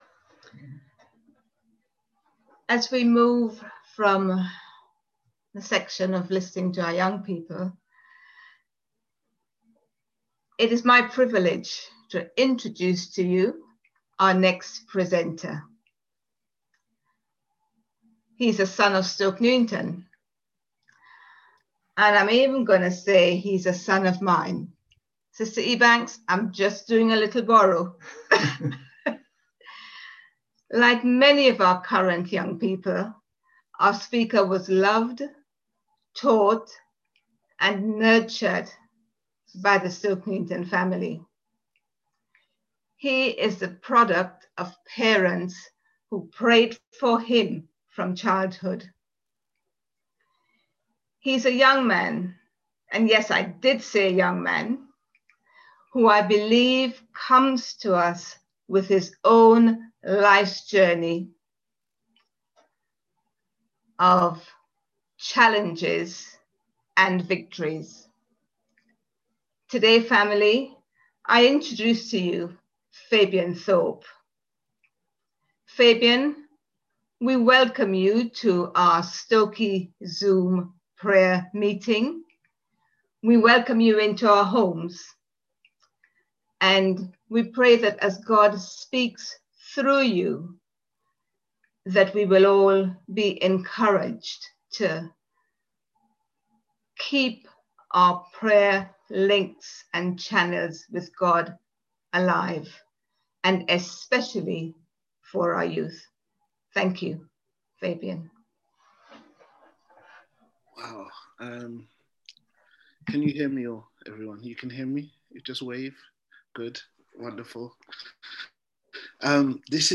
RL_Sermon___Instrumental.m4a